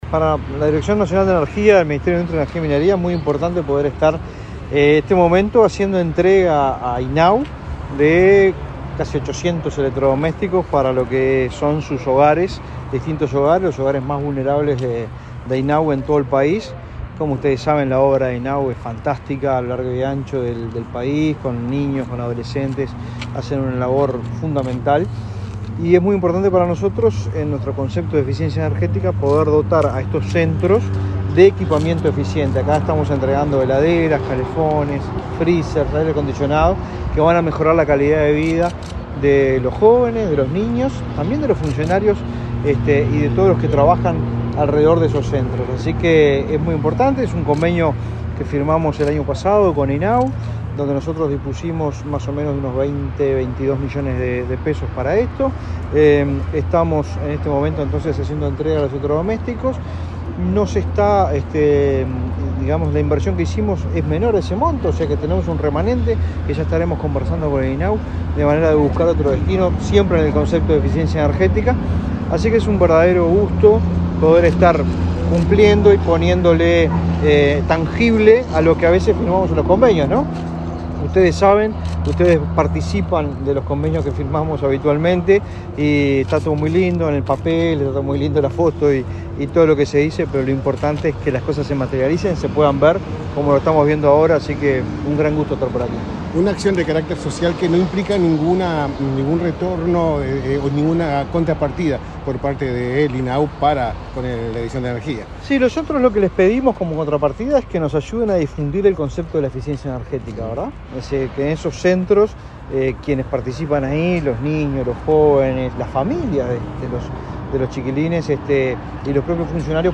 Declaraciones del director nacional de Energía